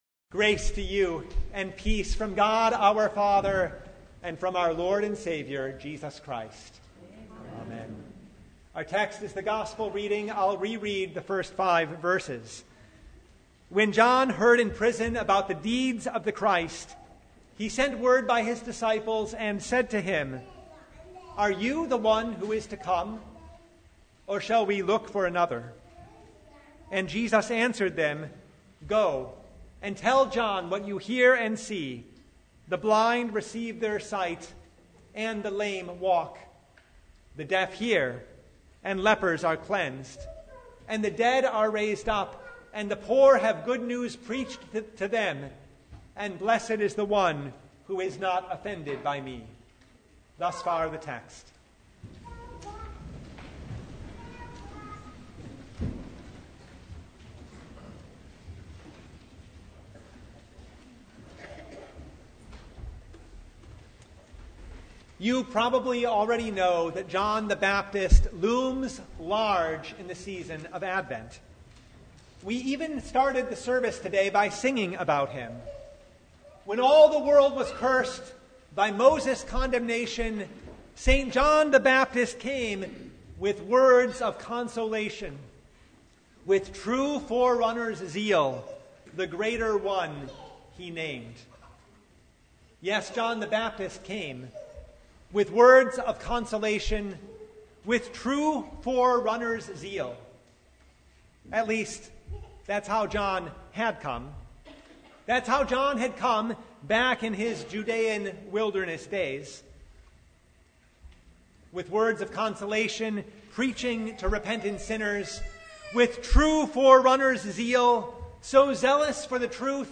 Download Files Notes Topics: Sermon Only « The Third Sunday in Advent, Gaudete!